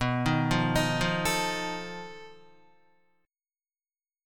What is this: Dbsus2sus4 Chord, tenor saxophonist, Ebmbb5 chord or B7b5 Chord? B7b5 Chord